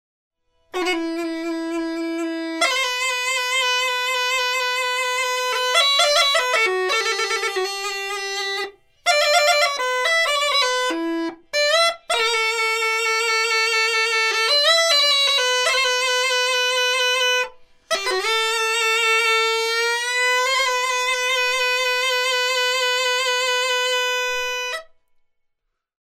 また他にも、 ガイダ（Gaida）ガドゥルカ（Gadulka）などの伝統的な民族楽器も登場します。